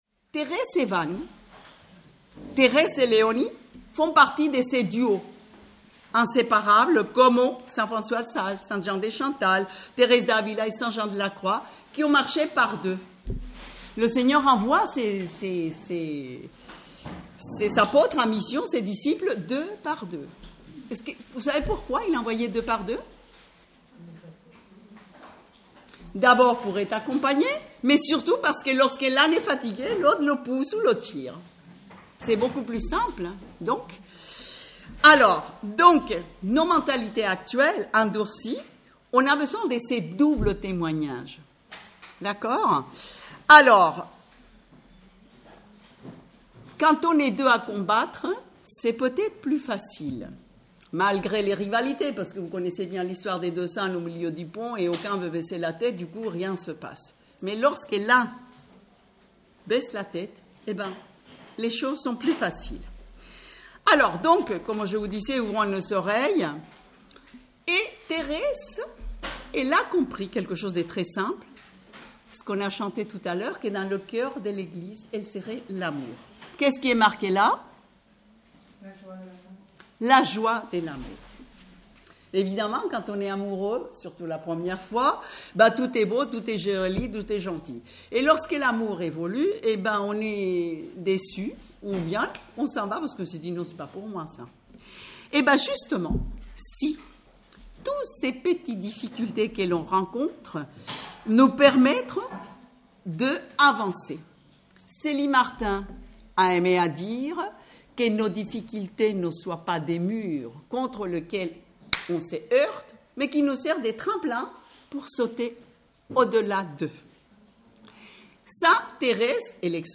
Enregistré lors de la session des Béatitudes à Lisieux en août 2017